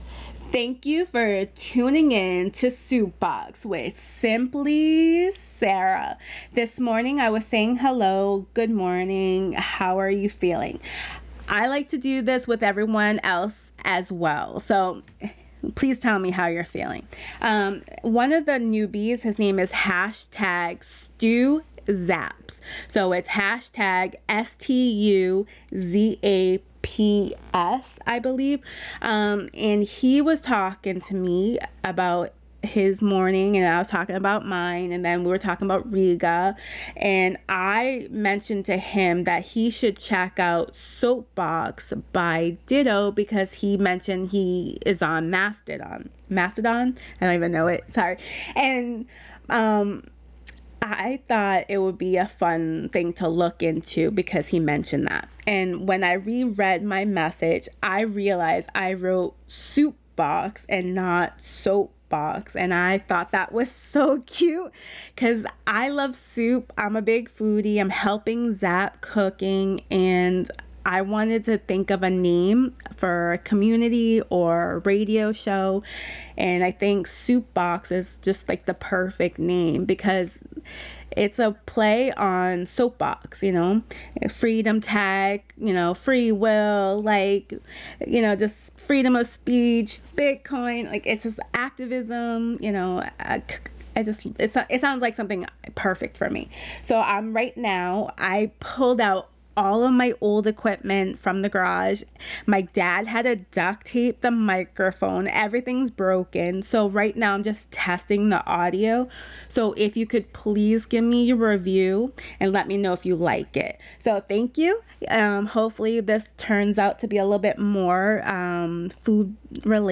This is a test run of sound quality from my broken equipment.
So please listen to see if you could tolerate the sound quality.